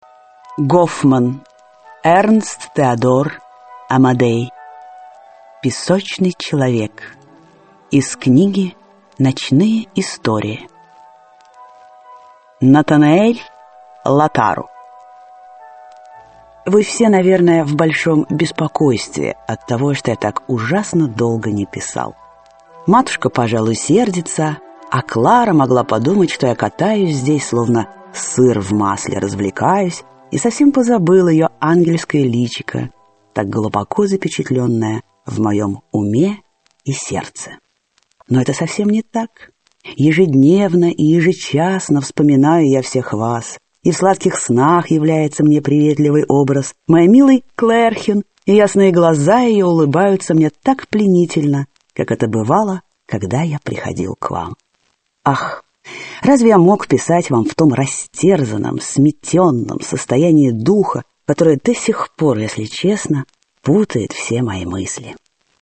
Аудиокнига Песочный человек | Библиотека аудиокниг